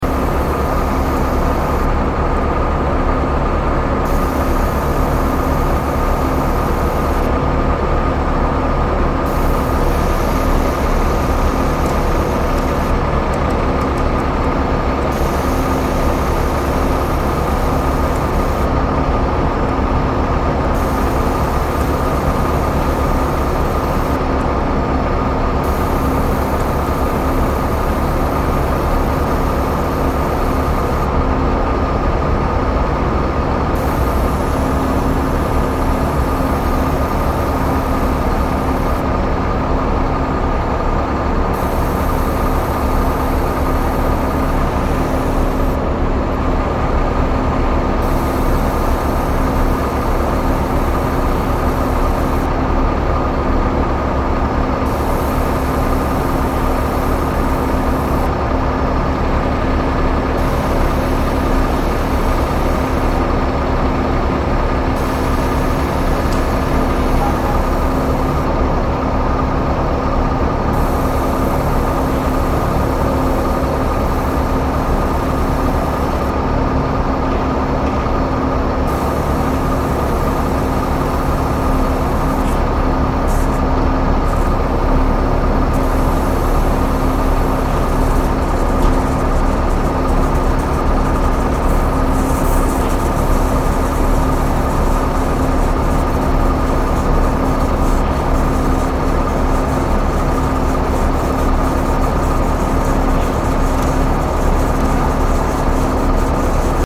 Качество записей проверено – никаких лишних шумов, только чистый звук техники.
Звук заливки бетона из миксера